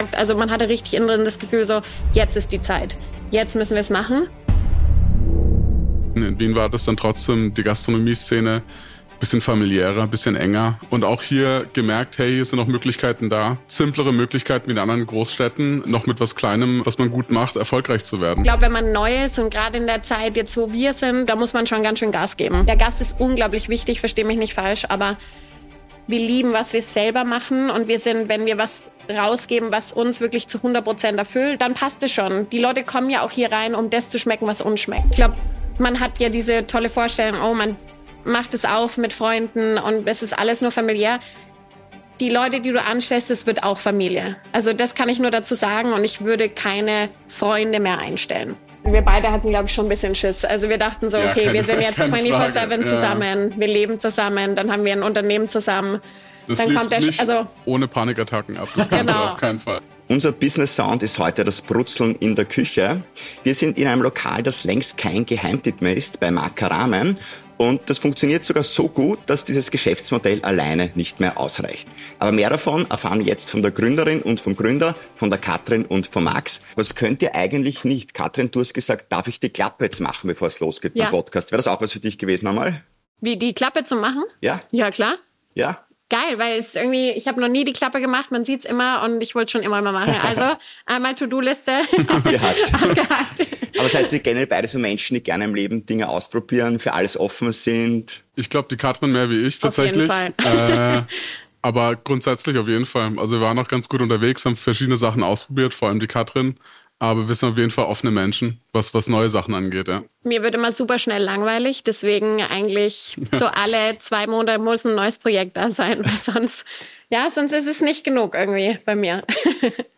Heute im Gespräch: